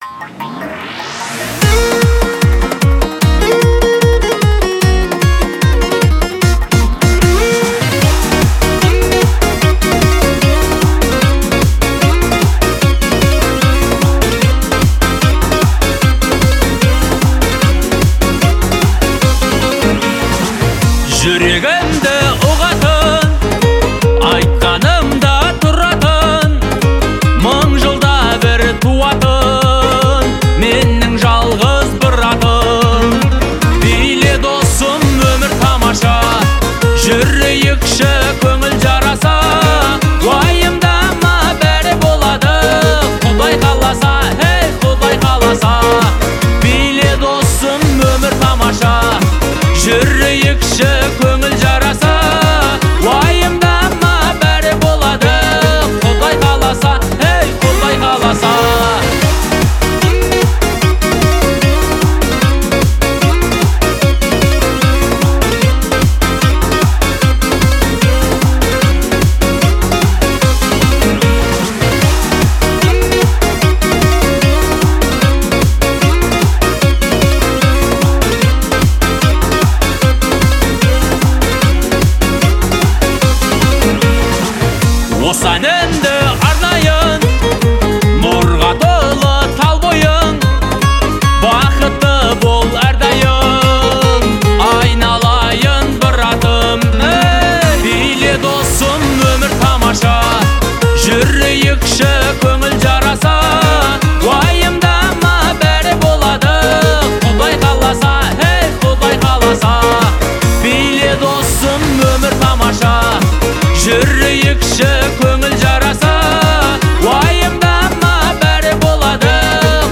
казахской поп-музыки